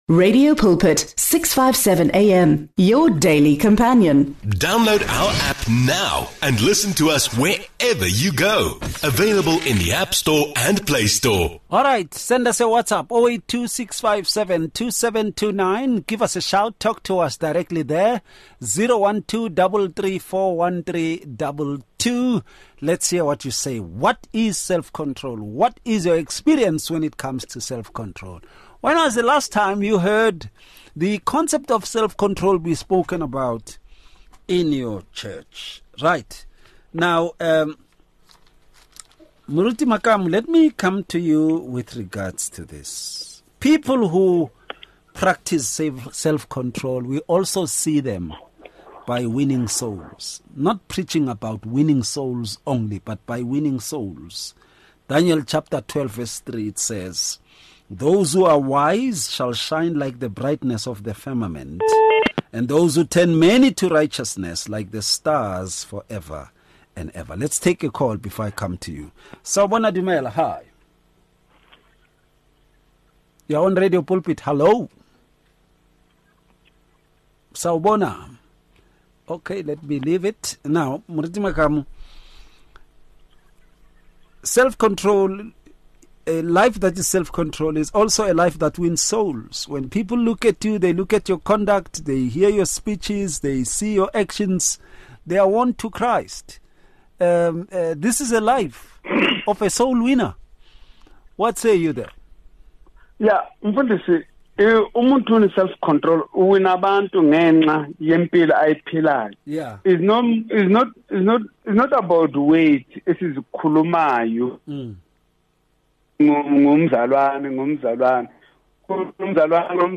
The conversation highlights self-control as a vital aspect of Christian character, rooted in biblical principles and empowered by the Holy Spirit. Each panelist shares practical insights and real-life examples, emphasizing the challenges and rewards of cultivating self-control in daily life. They agree that true self-control involves both personal discipline and spiritual dependence.